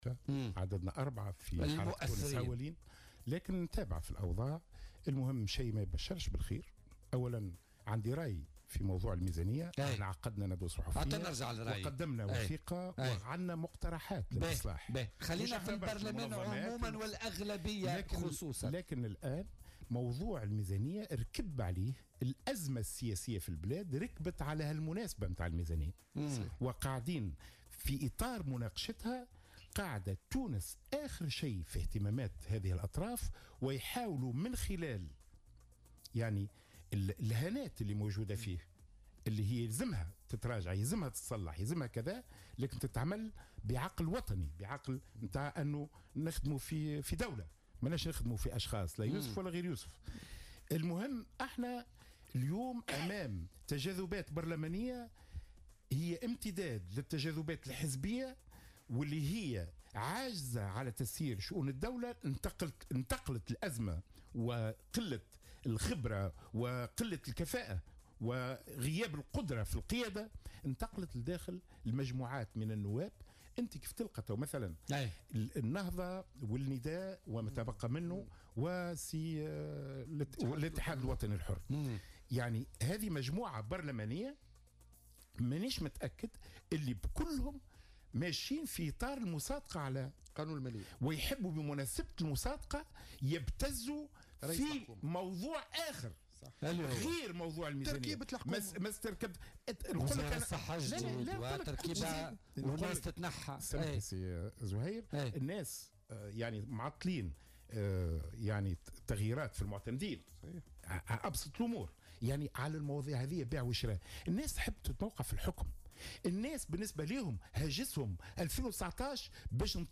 أكد القيادي في حزب تونس أولا خميس كسيلة ضيف بولتيكا اليوم الإثنين 13 نوفمبر 2017 أن هناك أجواء انقلابية تحوم حول المشهد السياسي في تونس.